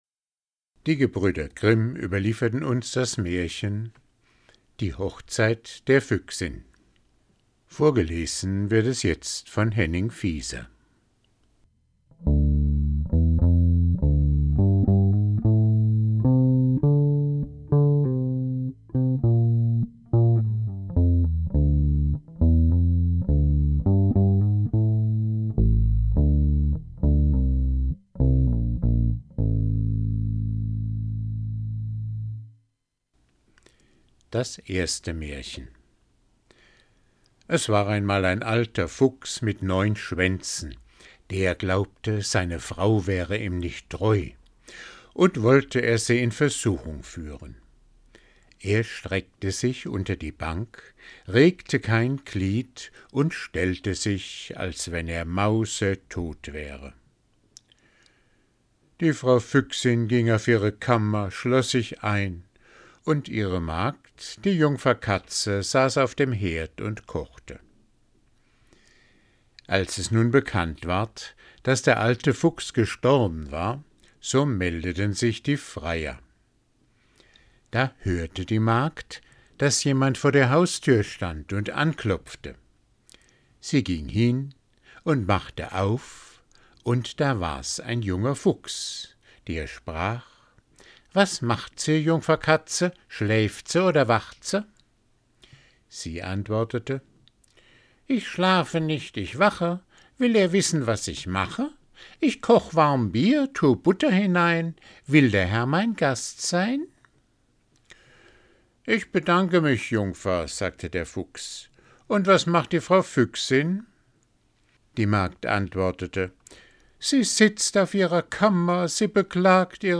Vorgelesen